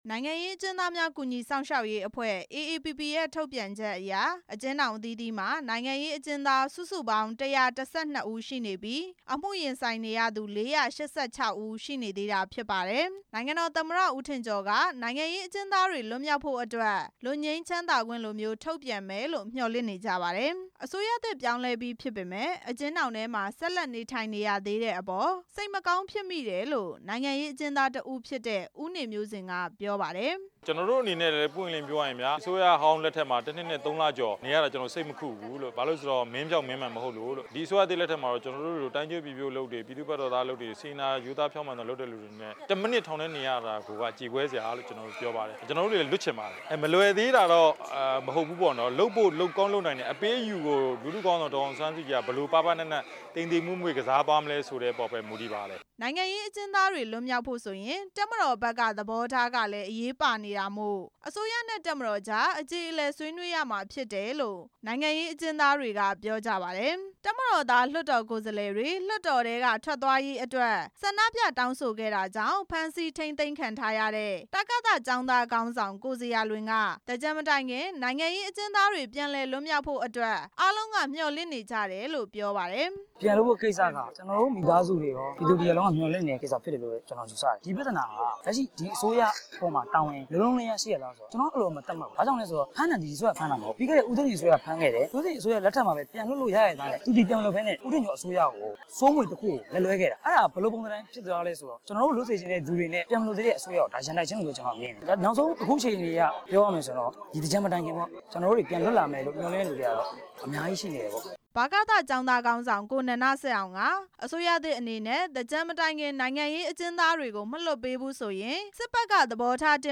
နိုင်ငံရေးအကျဉ်းသားတွေရဲ့ စကားသံတွေကို